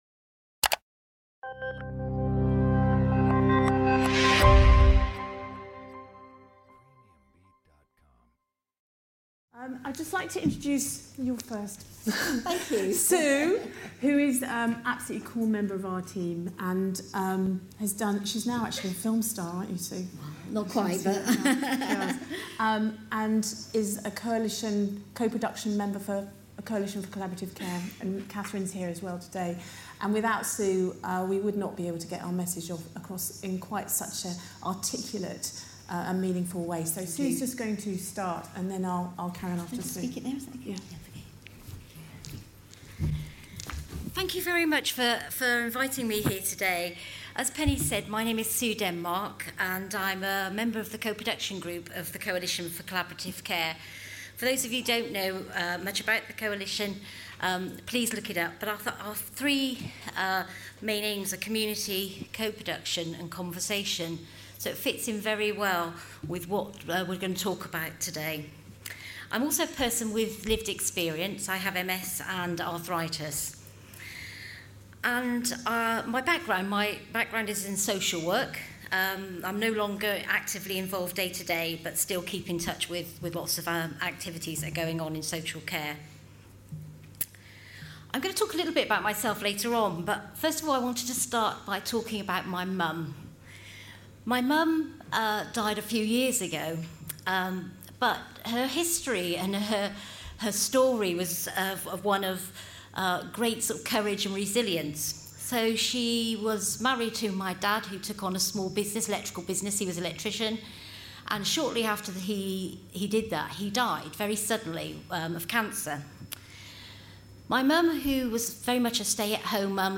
Recorded Live in Liverpool at Radisson Blu on 26th Sept 2016